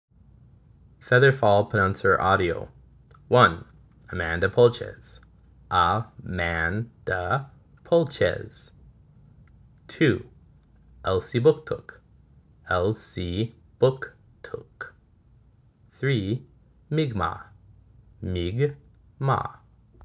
Pronunciations in Mi’kmaw language
• Elsipogtog → el-see-BOOK-TOOK
• Mi’kmaq → MIG-maw
frather-fall-audio-prouncer.mp3